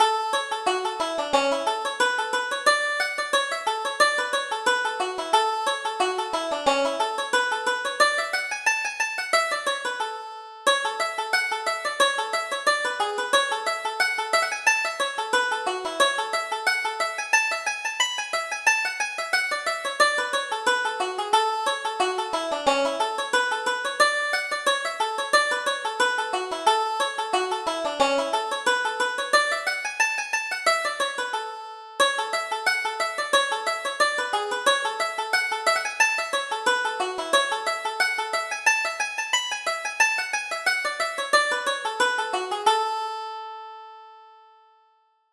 Reel: Music in the Glen